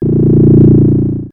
Hum13.wav